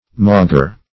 Mauger \Mau"ger\, Maugre \Mau"gre\ (m[add]"g[~e]r), prep.